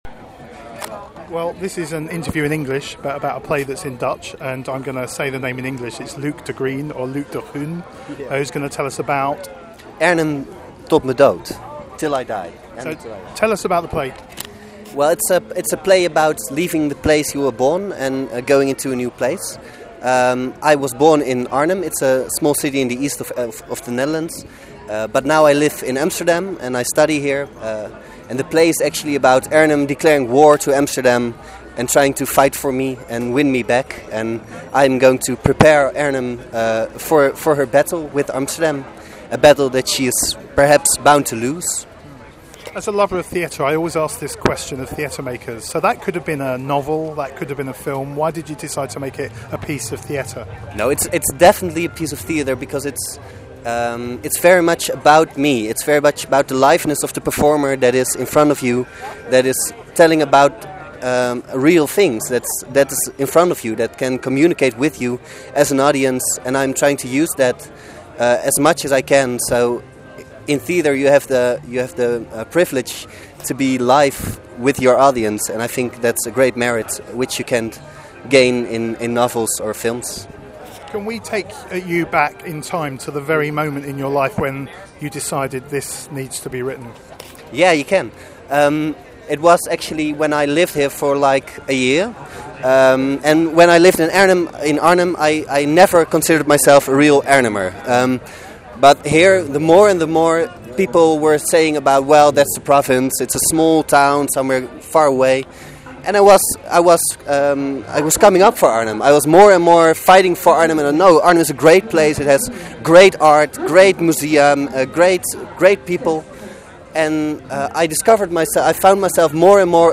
Amsterdam Fringe 2014, Audio Interviews